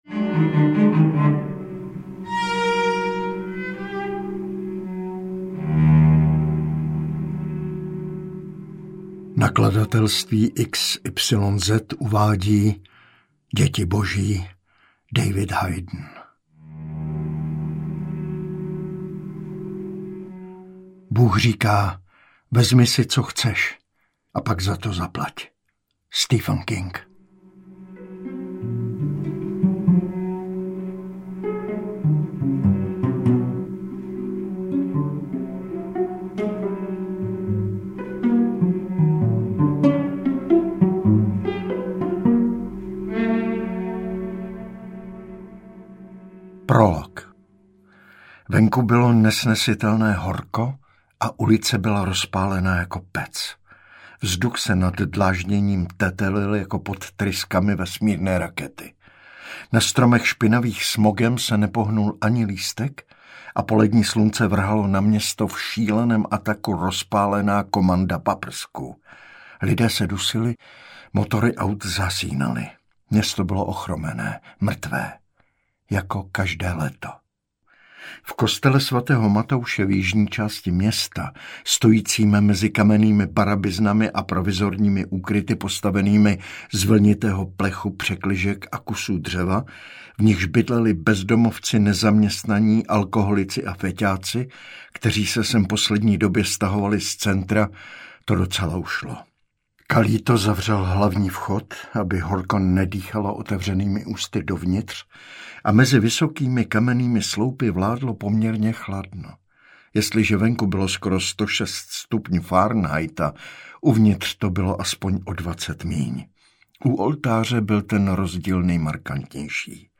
AudioKniha ke stažení, 34 x mp3, délka 8 hod. 25 min., velikost 576,2 MB, česky